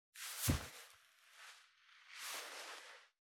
407,パーカーの音,衣服の音,衣類の音,サラッ,シャッ,スルッ,カシャッ,シュルシュル,パサッ,バサッ,フワッ,モソモソ,クシュッ,ゴソゴソ,カチャッ,シュッ,
効果音洋服関係